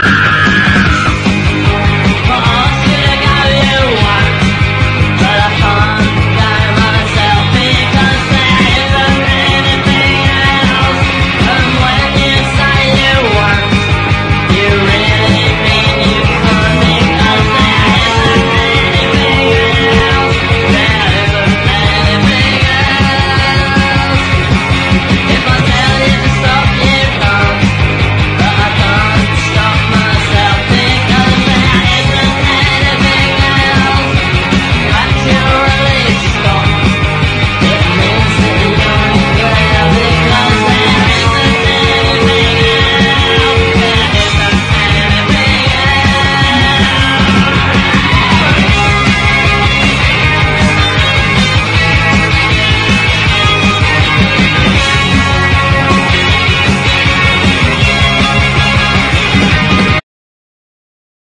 ROCK / 70'S / POWER POP / NEW WAVE / DISCO
デボラさんよりもキュートな歌い方が◎なのです。